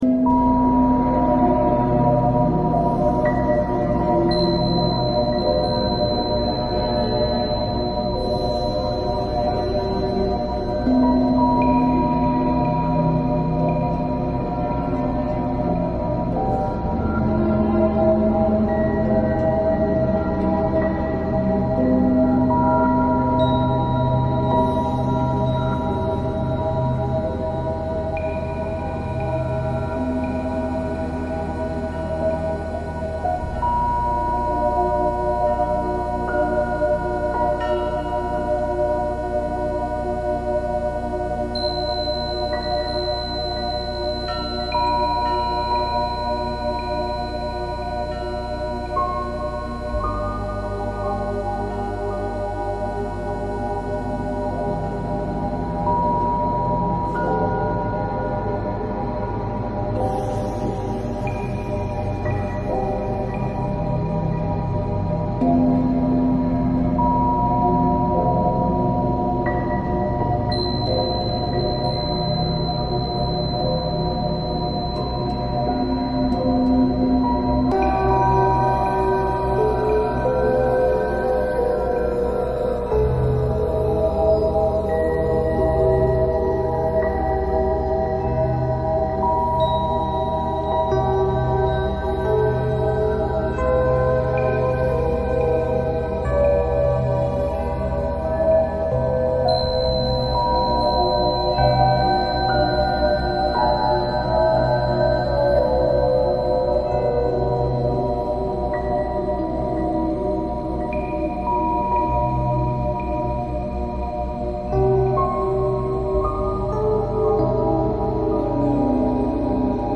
¿Sientes el llamado a despertar tu poder interior? La frecuencia 963 Hz, conocida como la frecuencia de Dios, estimula la glándula pineal, centro de tu intuición, sueños lúcidos y conexión espiritual. Combínala con los tonos Solfeggio y permite que tu consciencia se expanda.